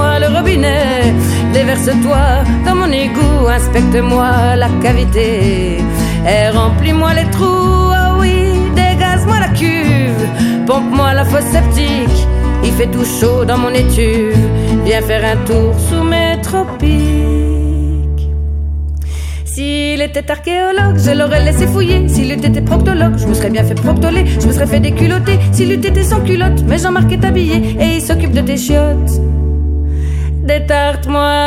Chansons francophones